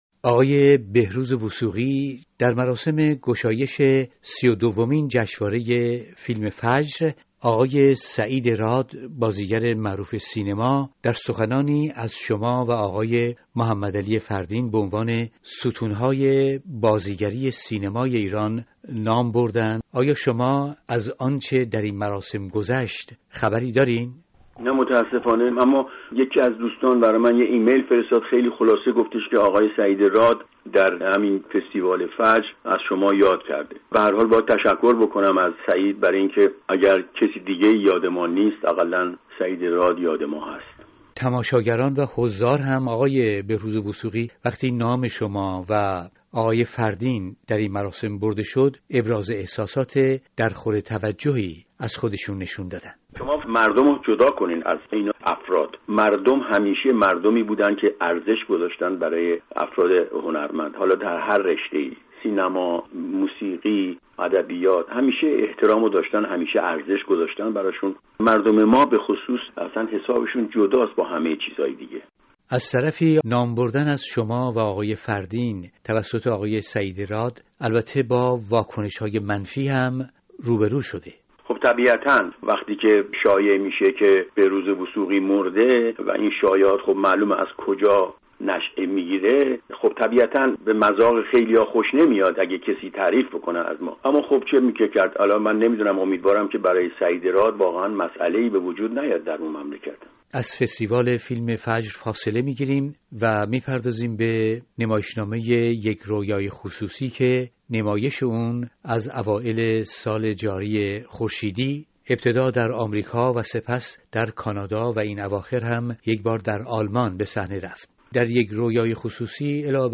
بهروز وثوقی در گفتگو با رادیو فردا،ابراز اميدواری کرده که نکو داشت نام او و فردين،مسئله و مشکلی برای سعيد راد ايجاد نکند.